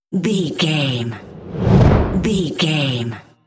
Whoosh deep fast
Sound Effects
Fast
dark
intense
whoosh